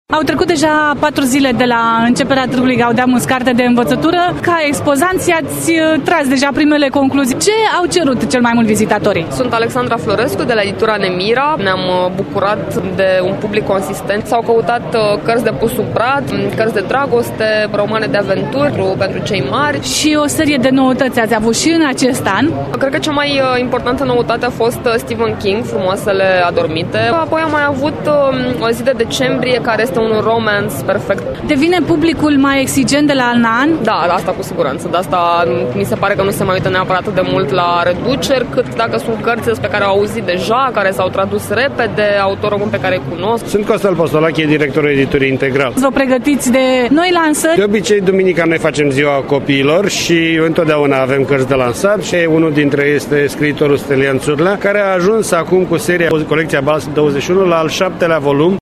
stiri-18-nov-expozanti.mp3